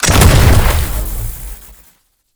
weapons
rifle2.wav